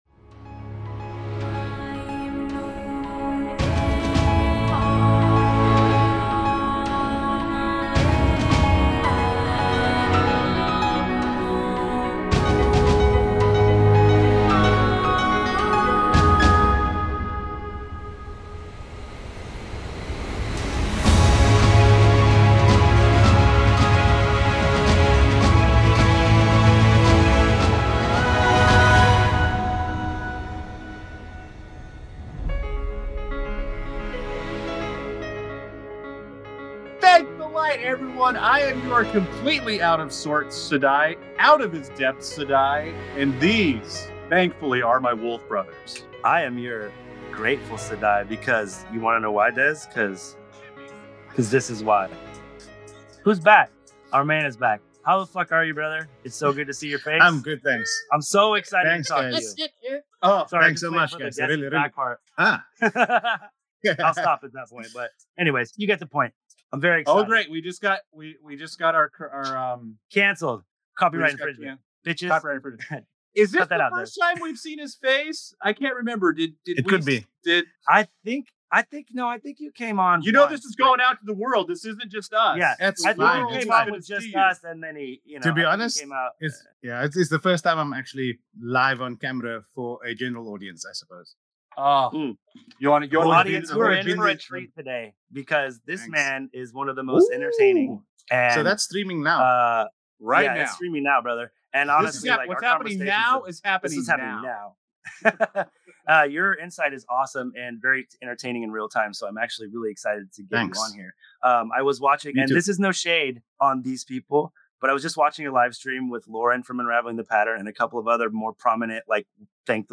In this episode, the excitement is palpable as our hosts reunite for a lively conversation about the upcoming season three of "The Wheel of Time."
The camaraderie shines through as they share laughs, engage in playful banter, and express their thoughts on the show's direction and production quality. As they dissect the nuances of the edits, the hosts reflect on the storytelling choices made in seasons one and two, questioning the effectiveness of certain plotlines while celebrating the moments that resonate.